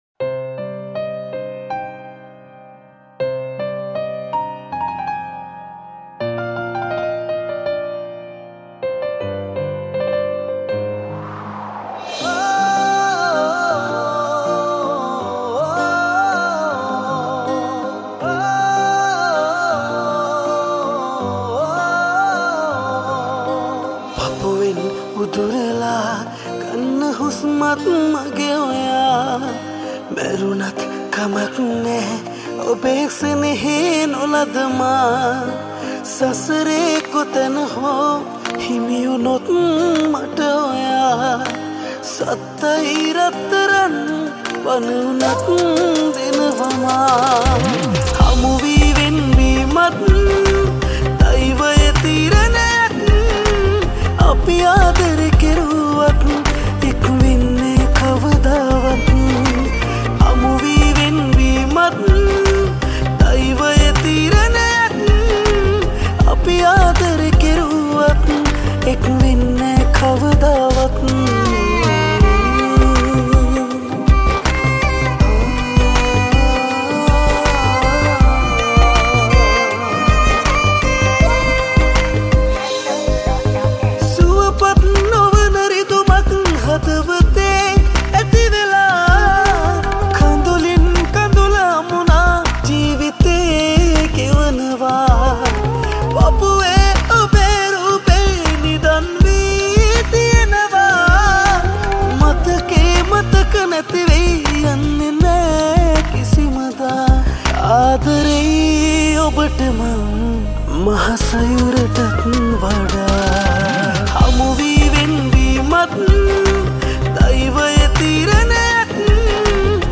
High quality Sri Lankan remix MP3 (3.4).
remix